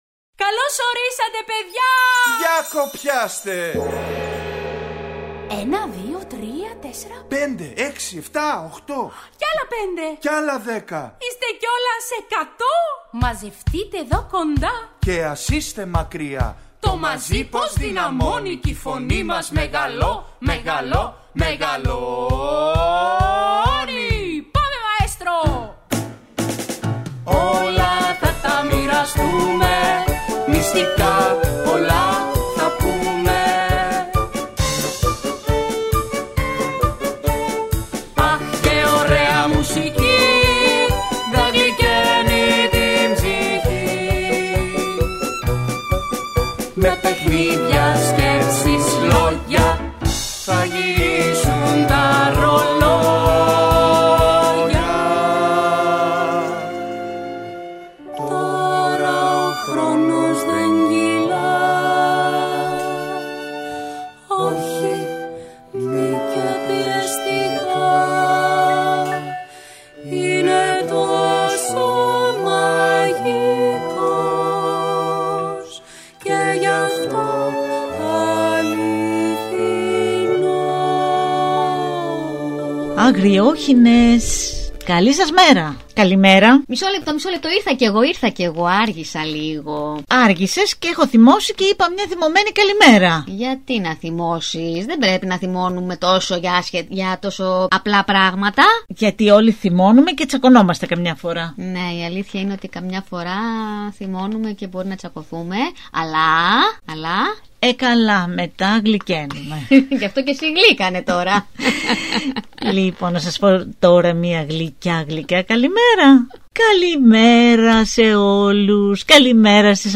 Ακούστε την τελευταία εκπομπή του σωματείου στο WebRadio του Π.Α.ΜΕ